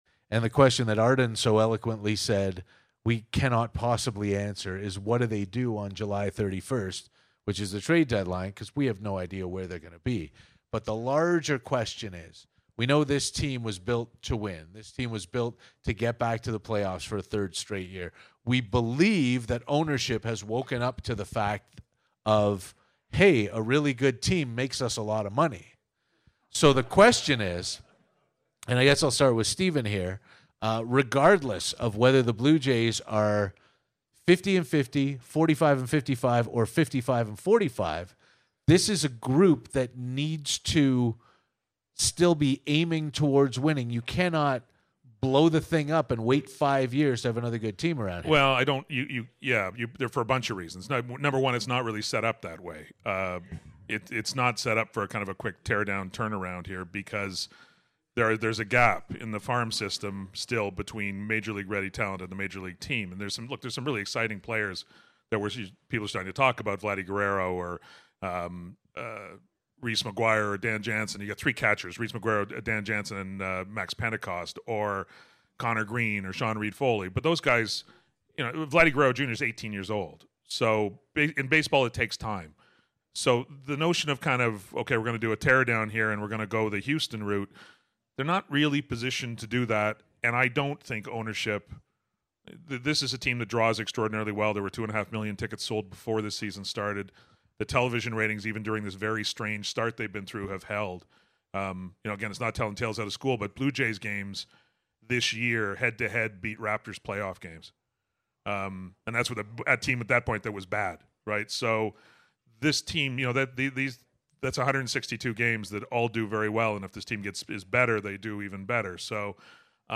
Panel 2 of Pitch Talks in Tavistock featuring: Mike Wilner Stephen Brunt Jeff Blair